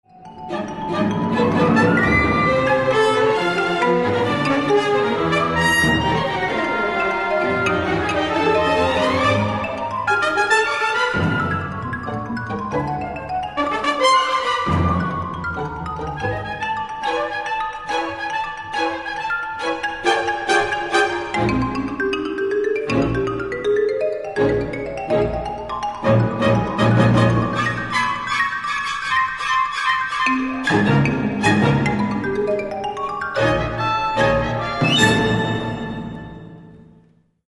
Australian, Classical, Orchestral